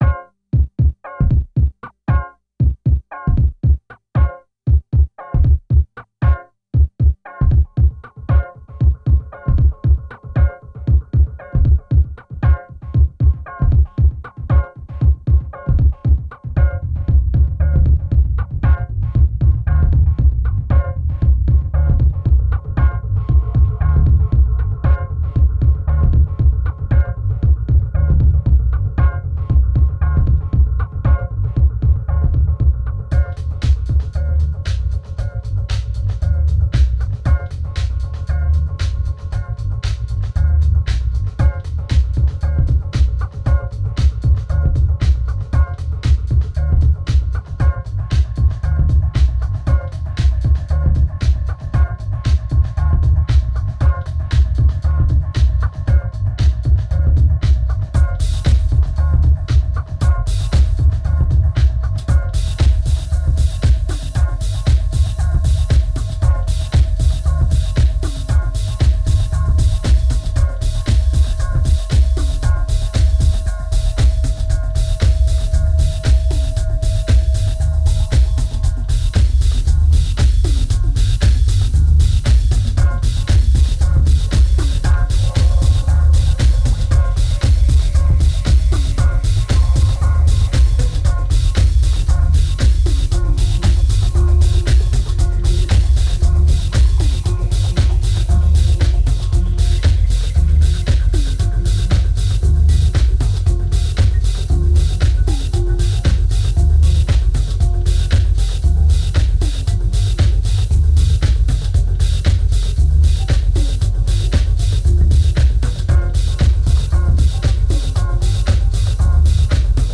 1964📈 - -21%🤔 - 116BPM🔊 - 2010-10-31📅 - -194🌟